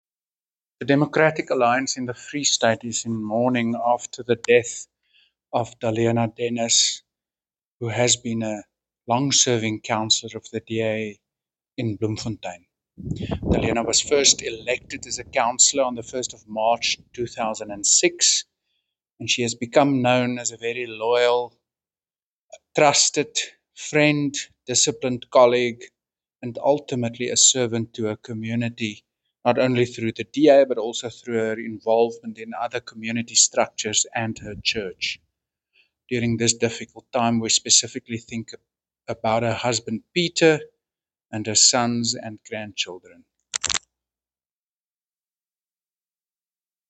Afrikaans soundbites by Werner Horn MP and Sesotho soundbite by Jafta Mokoena MPL.